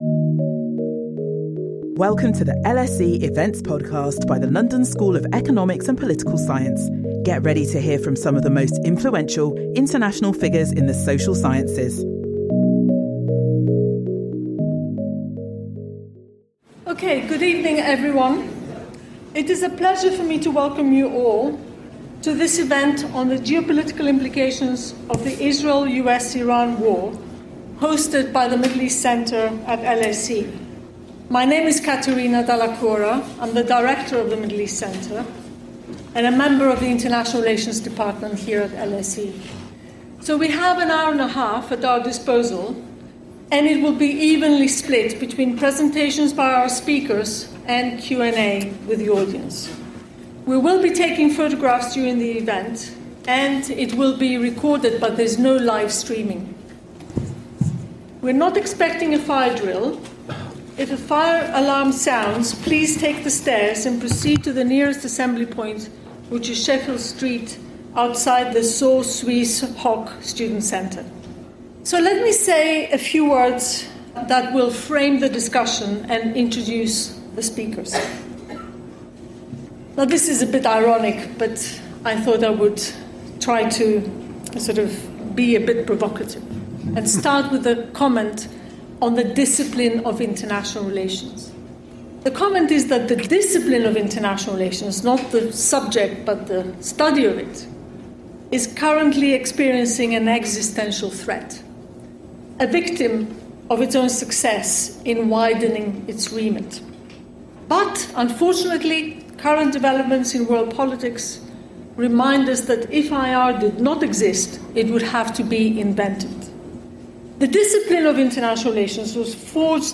A panel of academic experts is brought together by the Middle East Centre at LSE to discuss the current Israel-US-Iran war.